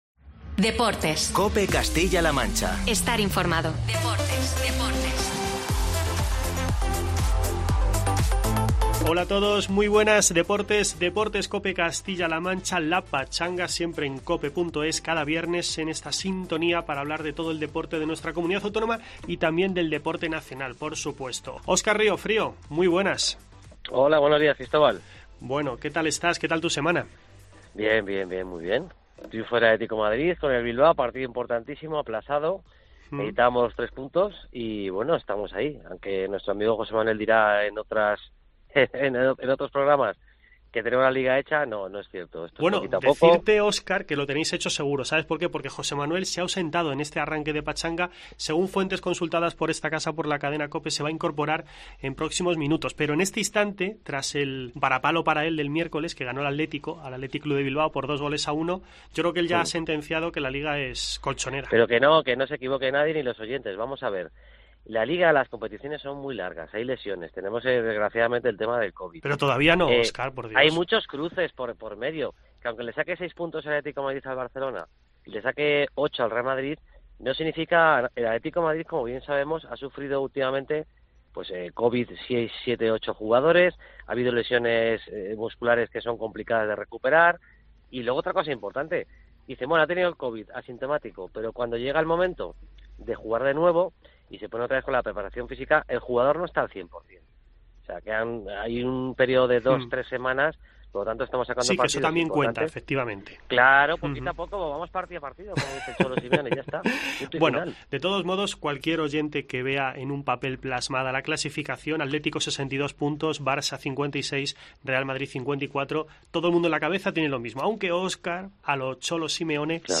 No te pierdas la entrevista con el deportista madrileño afincado en Guadalajara